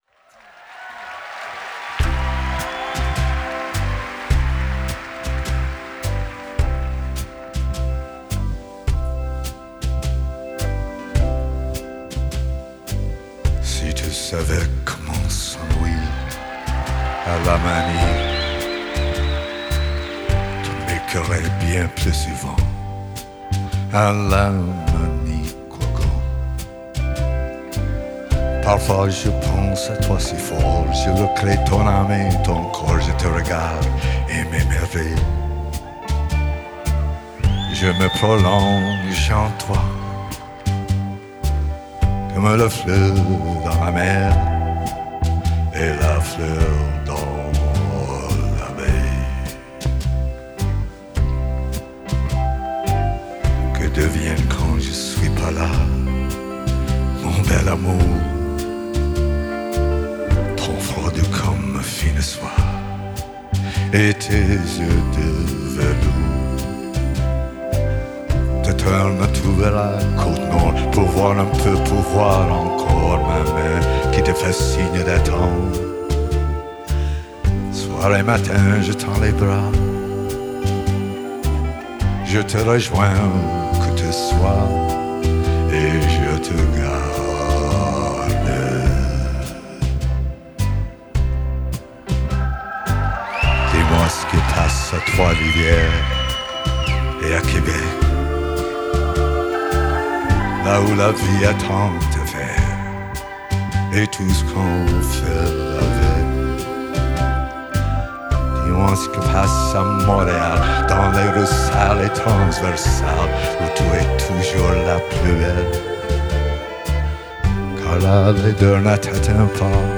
Genre: Folk, Blues, Singer-Songwriter
Live at Québec City Show, 2012